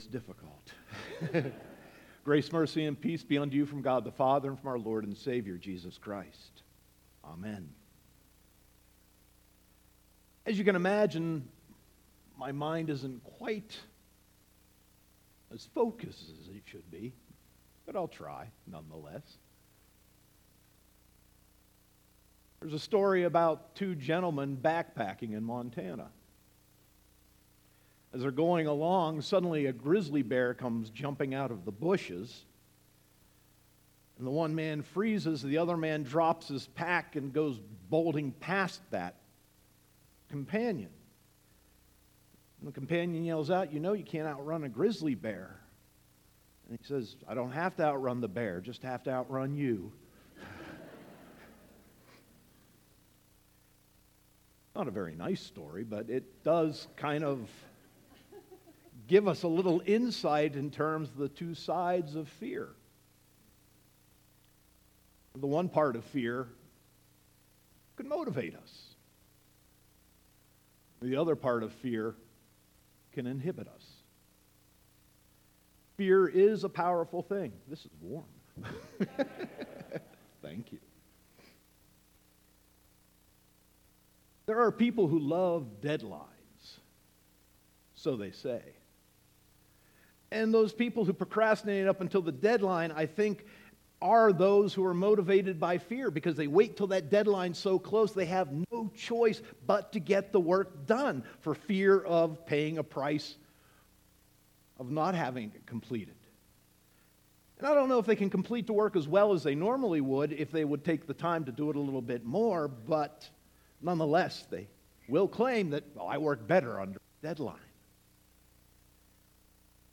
Sermon 4.8.2018